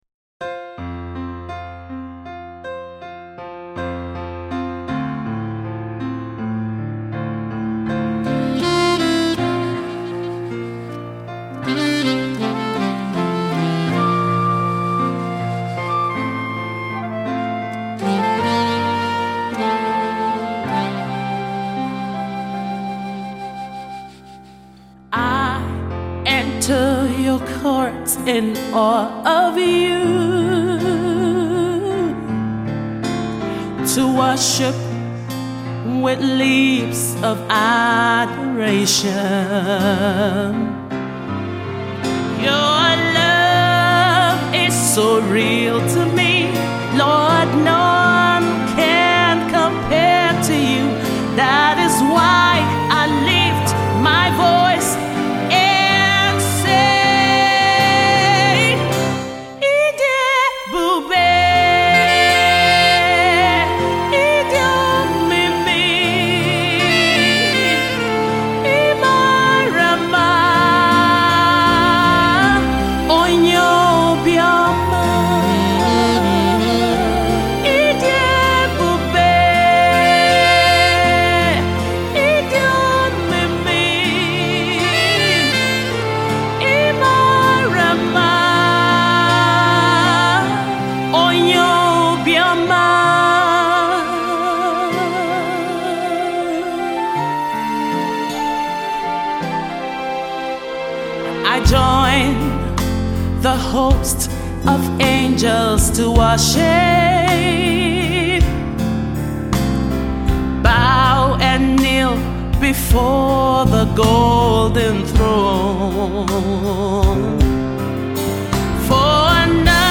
” a song of worship that extols the name of God.